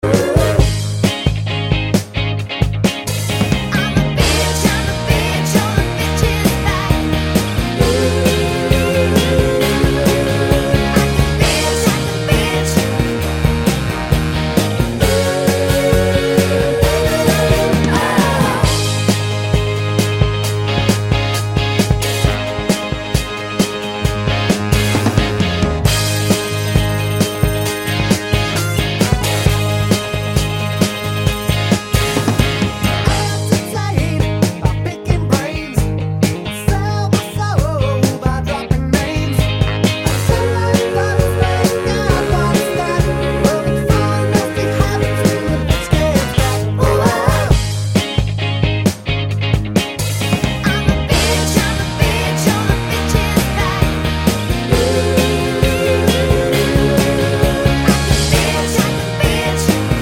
Minus All Saxes Pop (1970s) 3:38 Buy £1.50